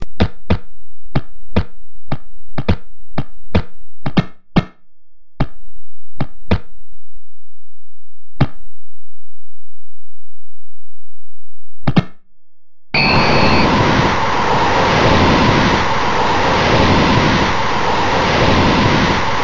Il suono si afferma al di sopra della media. Si ravvisano occasionali campionamenti da stadio, effetti d’impatto generalmente riusciti; l’assenza di una traccia introduttiva non sembra infastidire di fronte all’apporto di programmazione, ancor di più se si considera che il gioco risiede nelle ristrettezze di un unico disco da 880.